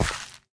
assets / minecraft / sounds / step / gravel1.ogg
gravel1.ogg